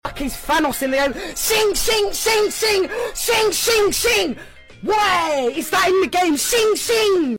Streamer Mongraal Shing Shing Shing sound effects free download